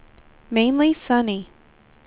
speech / tts / prompts / voices
weather_65.wav